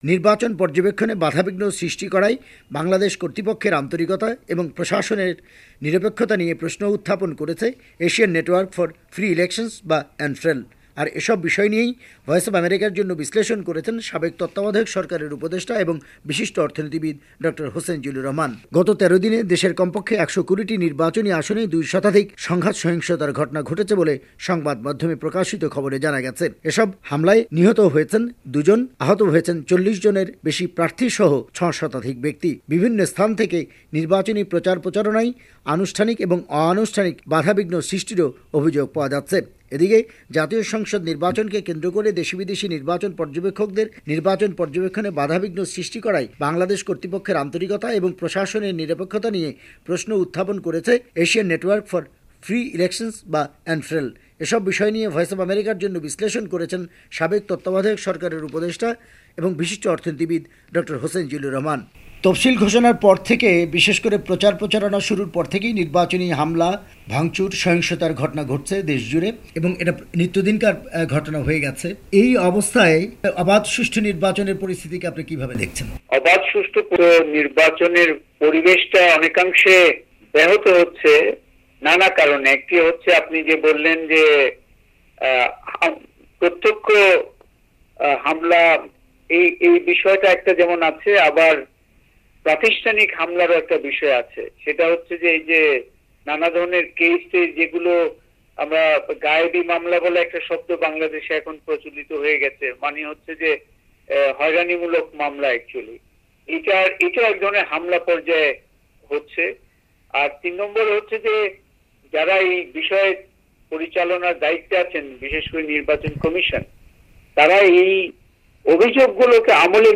ঢাকা থেকে
রিপোর্ট